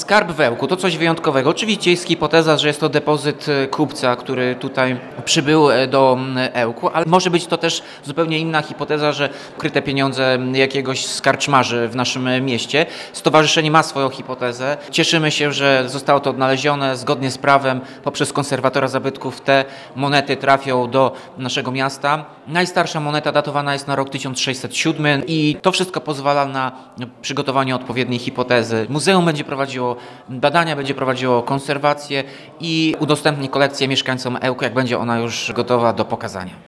Efekt- ponad 500 monet. Jak mówi Tomasz Andrukiewicz, prezydent miasta, ten skarb zostanie w Muzeum Historycznym w Ełku.